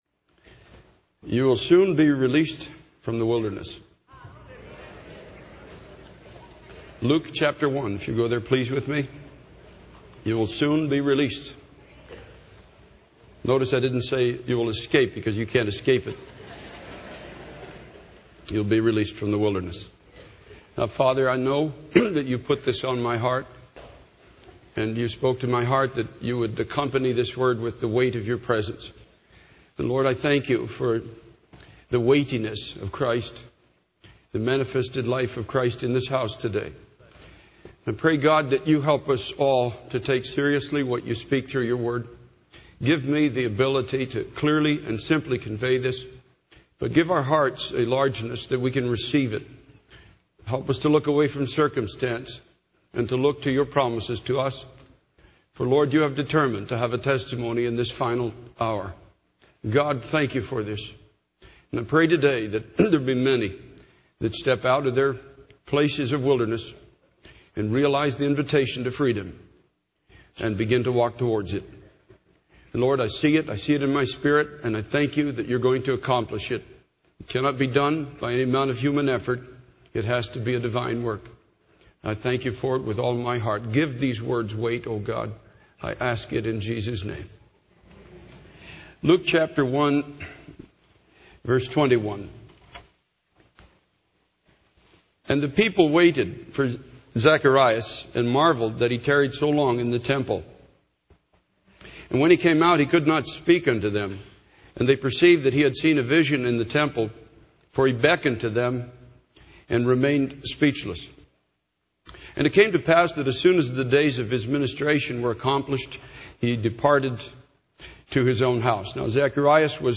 In this sermon, the preacher emphasizes the importance of living a consistent Christian life. He warns against the hypocrisy of attending church on Sundays but living contrary to God's word during the rest of the week.